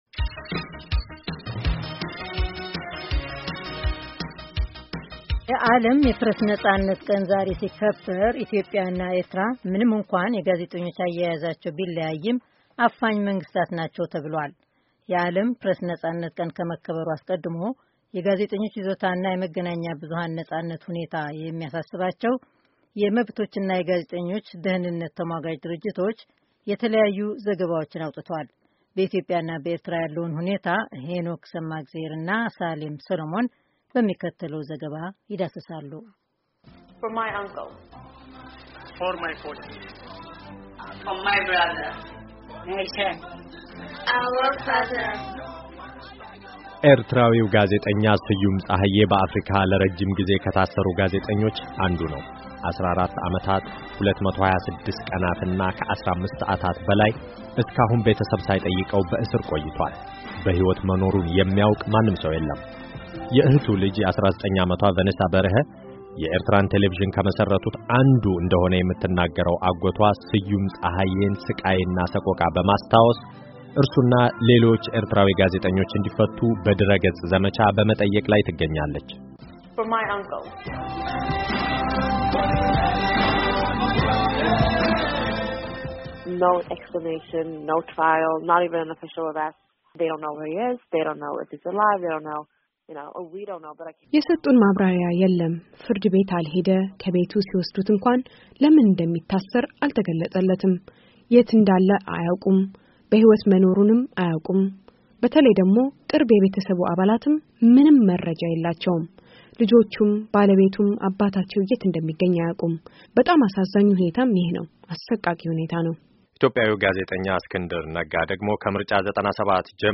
በሚከተለው ዘገባ ይዳስሳሉ።